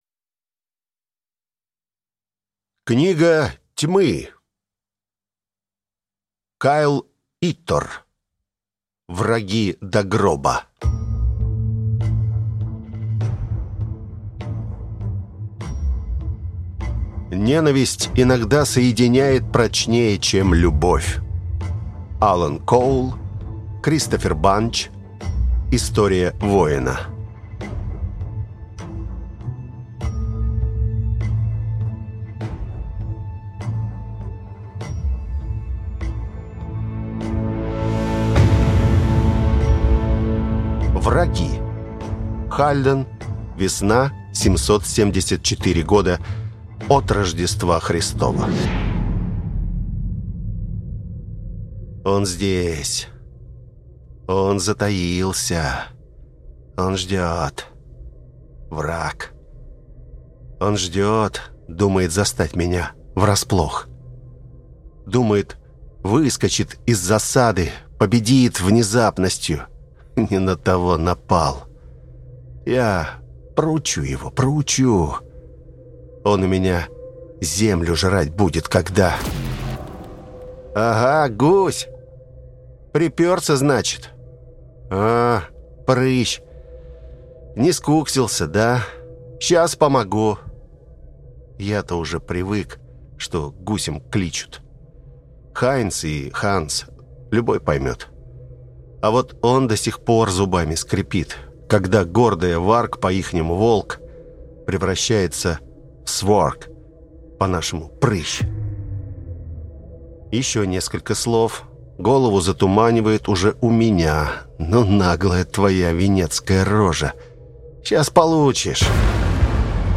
Аудиокнига Враги до гроба | Библиотека аудиокниг